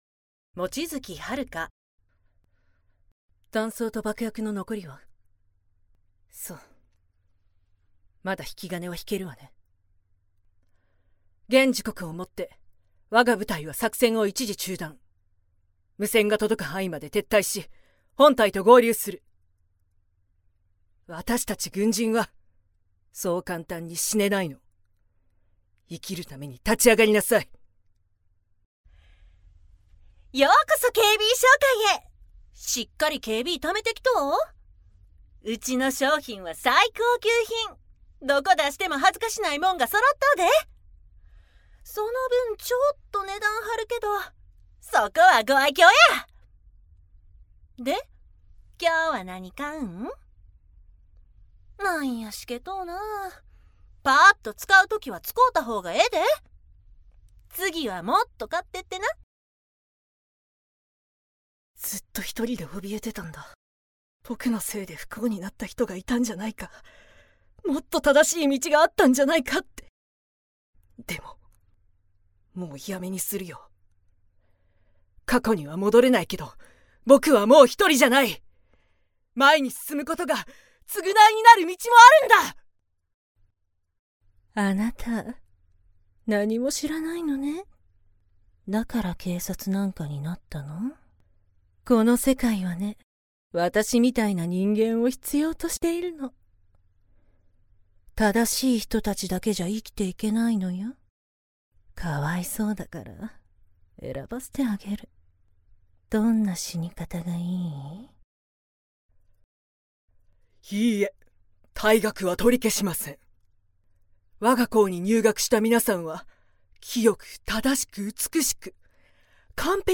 セリフ
女性タレント